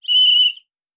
Whistle Blow Normal.wav